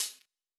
Hat (13).wav